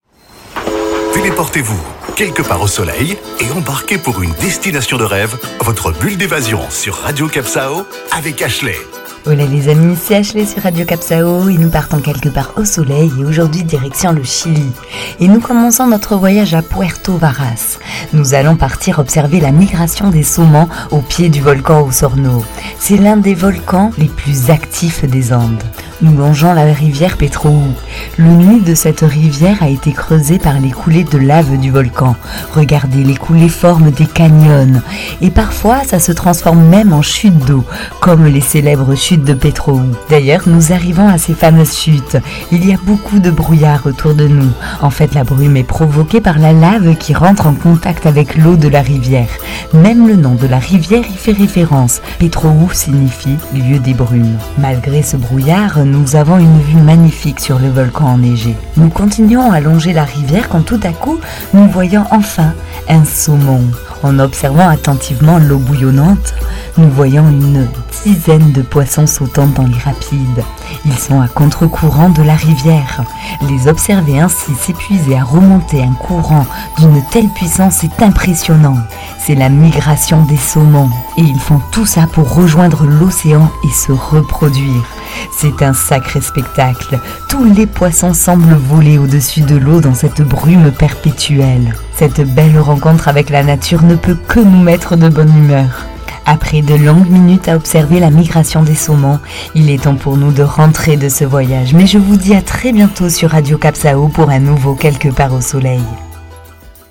Carte postale sonore : afin de profiter du spectacle de la migration des saumons vers l'Océan Pacifique, il faut d'abord passer par un paysage lunaire : départ de Puerto Varas au pied du Volcan Osorno et de la rivière Petrohue entre lave, brume et neige en plein coeur des Andes...